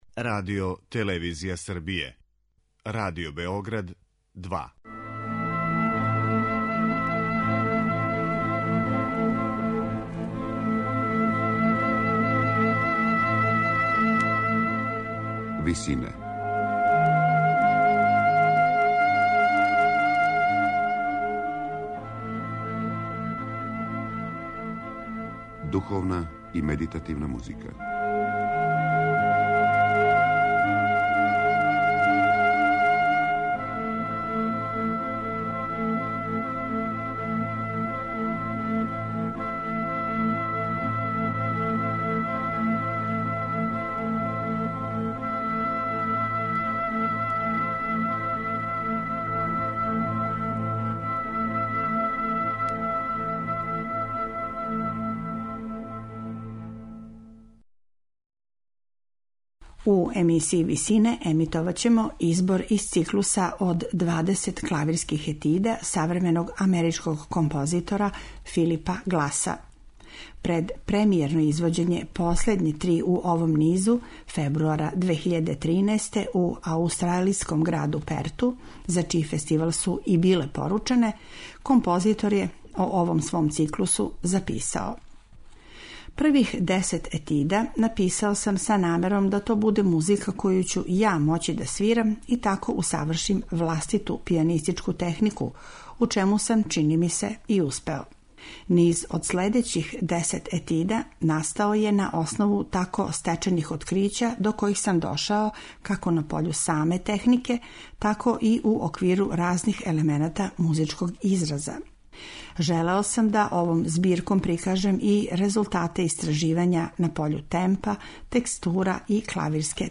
медитативне и духовне композиције
а свирају пијанисткиње